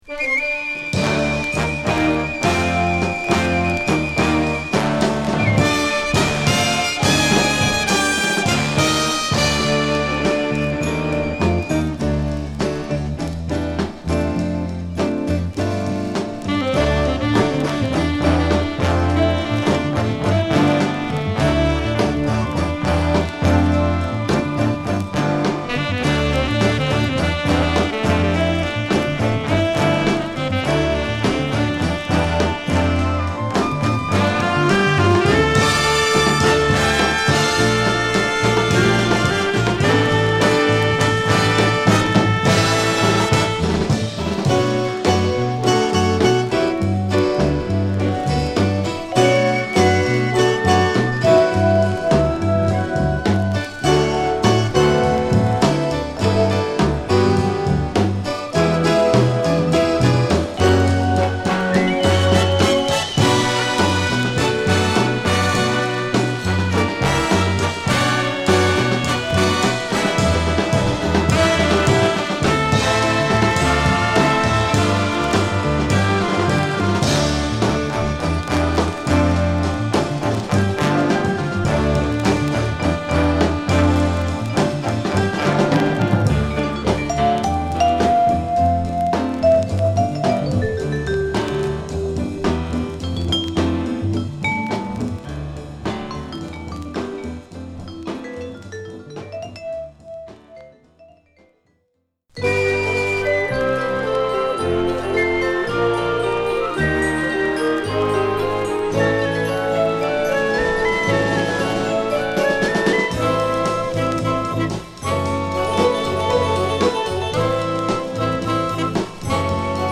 ソフトなカヴァーながらも中盤ではヴィブラフォンやホーンがカッコ良く鳴るナイスヴァージョンです。